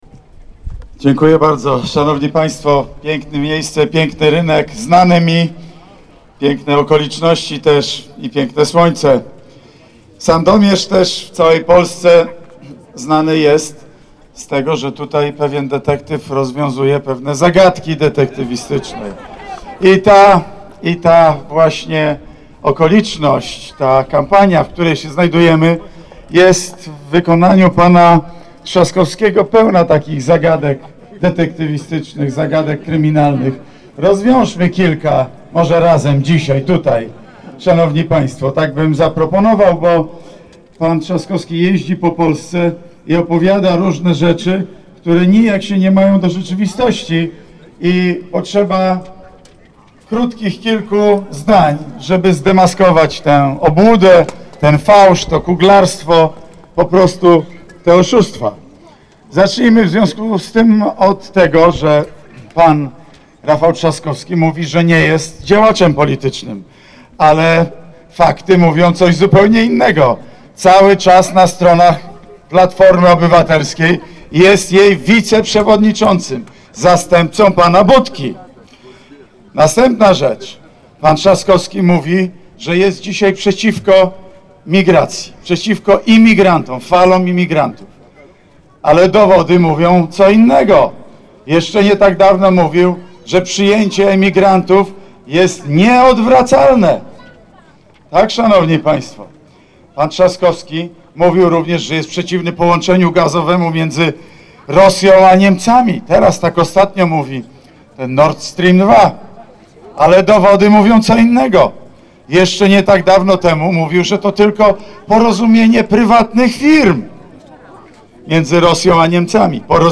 Premier Mateusz Morawiecki odwiedził dziś Sandomierz. Na rynku Starego Miasta spotkał się z mieszkańcami, których przekonywał do głosowania w drugiej turze wyborów prezydenckich na Andrzeja Dudę: